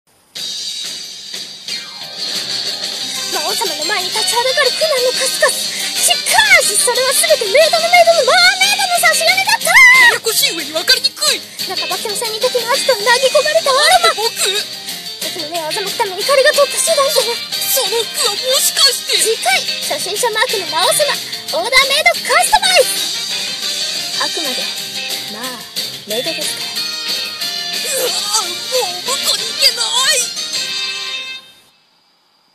【声劇】架空アニメの次回予告 その2(先輩魔族版)